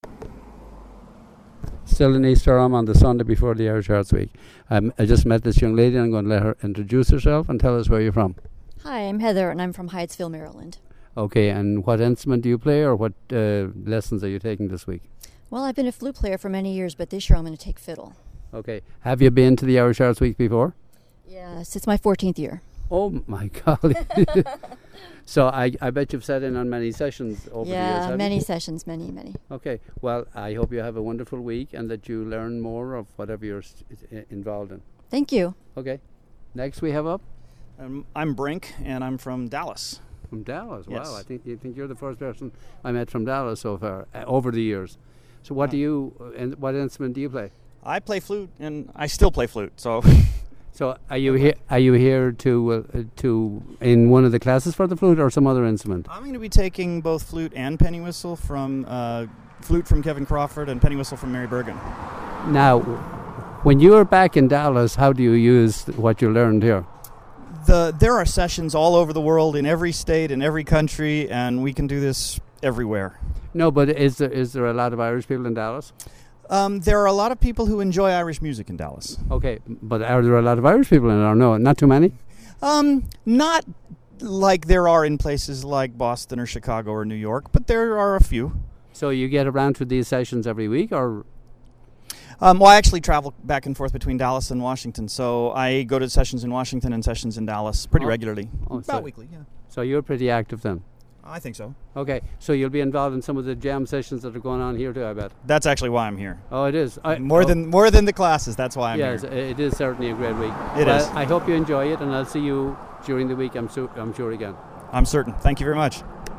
Musicians at Irish Arts Week 2014 (Audio)
IrishArtsWeek2014_Musicians.mp3